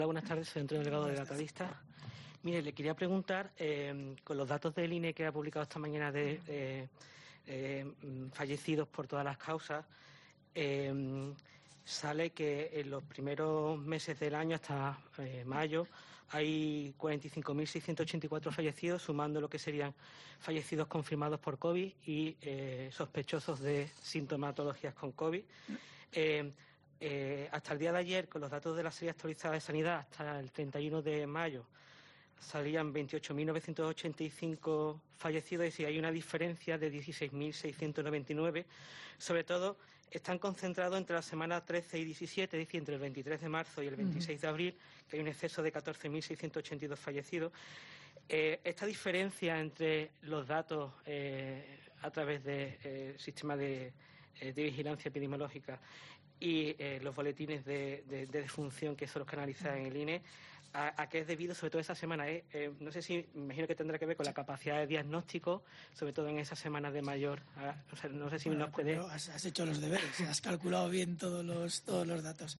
El director del Centro de Coordinación de Alertas y Emergencias Sanitarias ha valorado positivamente el trabajo de un periodista que acudía a la rueda de prensa